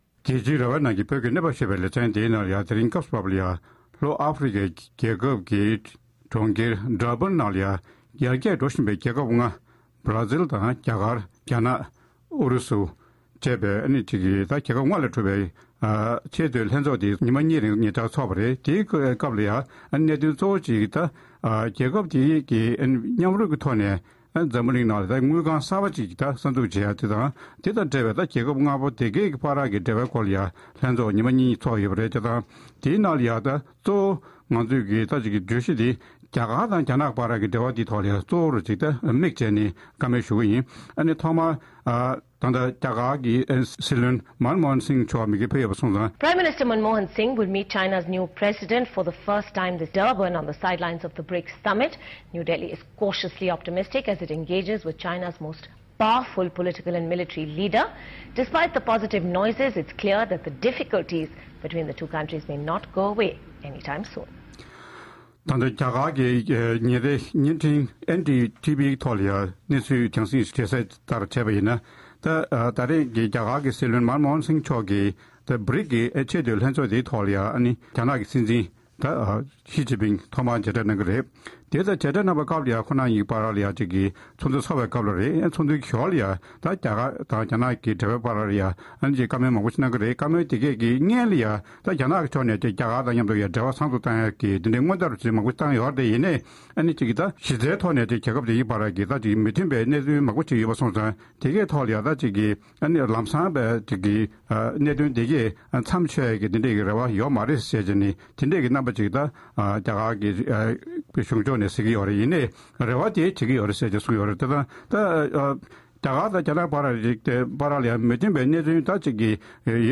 BRICS ཚོགས་ཆེན་ཐོག་གླེང་མོལ།